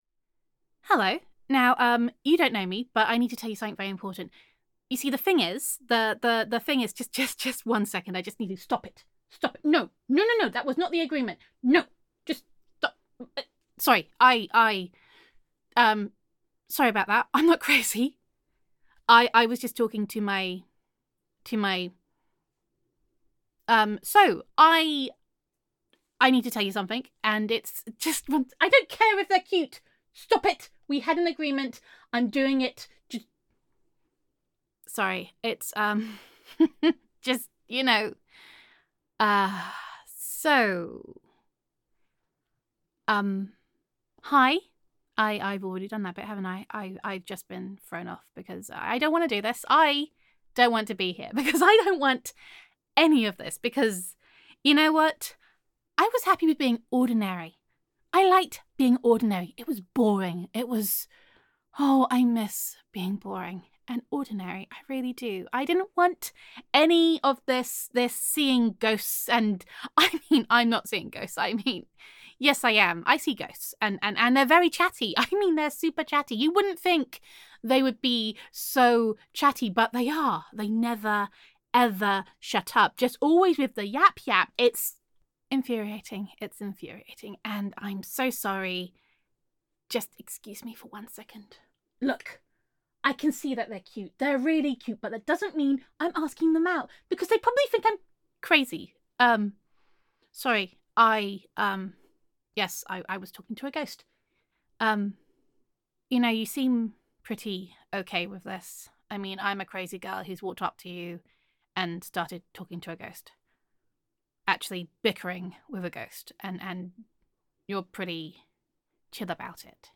[F4A] A Strange Couple of Weeks [Portly the Ghost Corgi][Warnings From Beyond the Veil][Utterly Ridiculous][Gender Neutral][Accosted by a Not-So-Delusional Reluctant Fortune Teller]